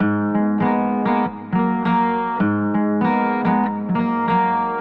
原声吉他Lo Fi风格 Groove
描述：原声吉他调低了半级。凉爽、冷酷的原声吉他旋律。
Tag: 100 bpm LoFi Loops Guitar Acoustic Loops 832.28 KB wav Key : A Cubase